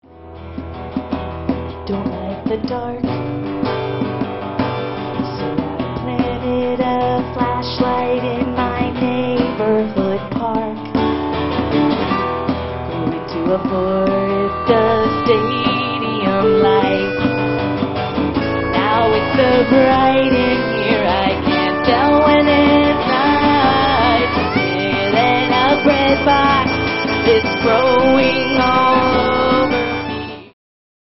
violin & acoustic guitar
ukulele, cardboard bass, and drum
live at Komotion, San Francisco